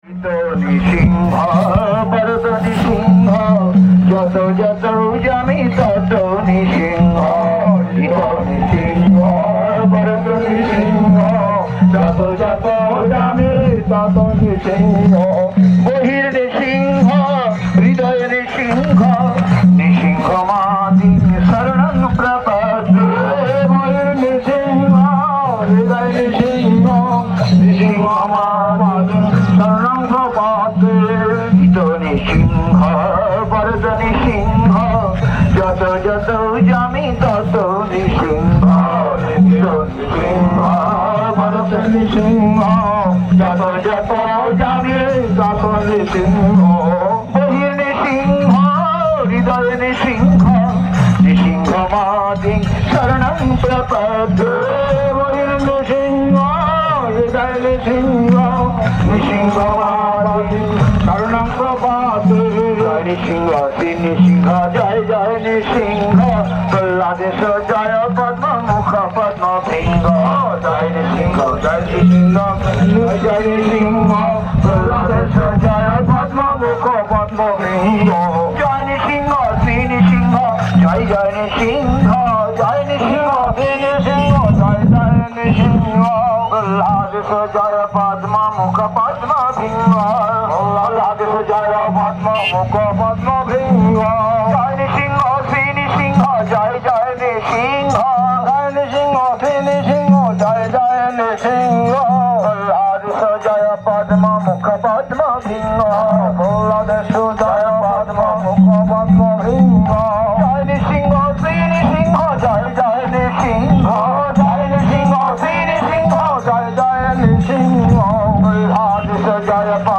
Gaura Purnima Parikrama 2012
Place: SCSMath Nabadwip
Tags: Kirttan